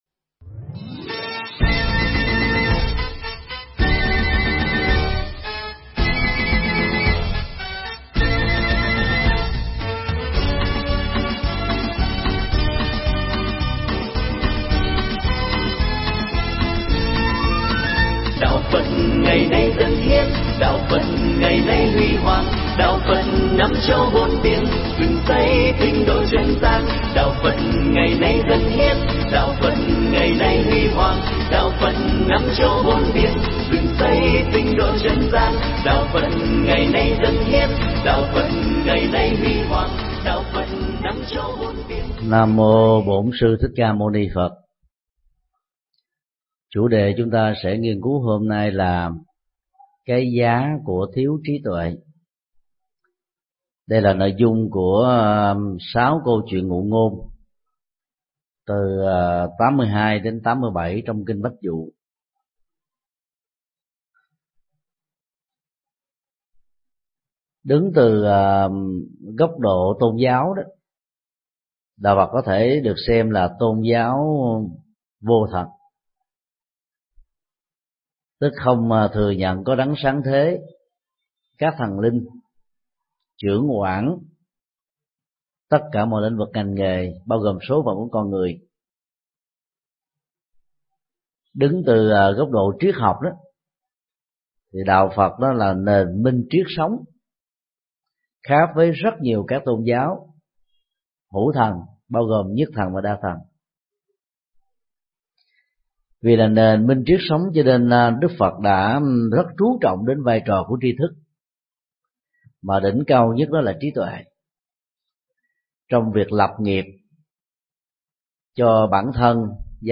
Mp3 Pháp thoại Kinh Bách Dụ 18 (Bài 82 – 87): Cái giá của thiếu trí tuệ
giảng tại chùa Giác Ngộ